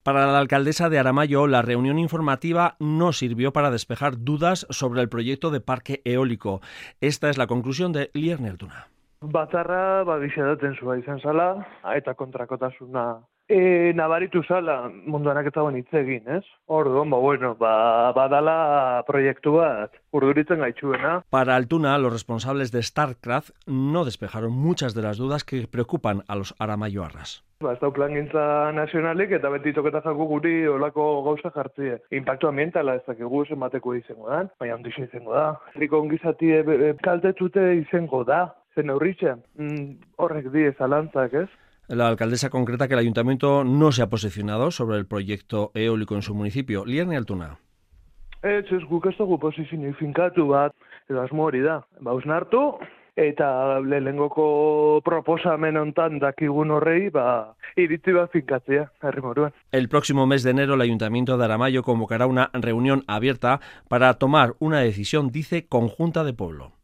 Después de la sesión informativa sobre el proyecto de parque eólico en Aramaio, la alcadesa de Aramaio ha hablado en Radio Euskadi.